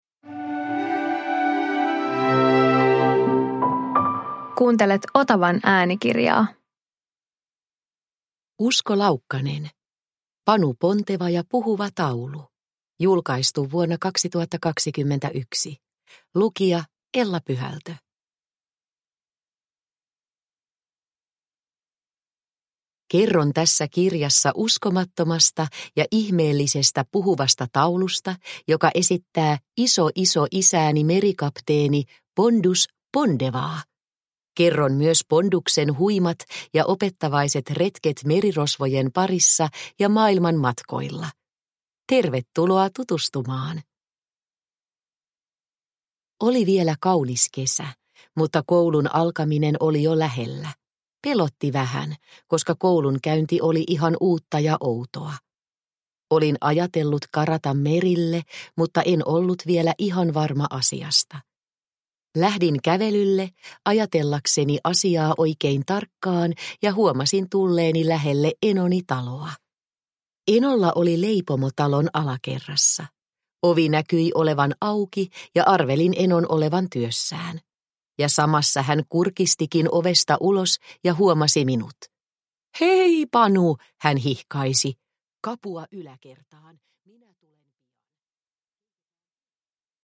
Panu Ponteva ja puhuva taulu – Ljudbok – Laddas ner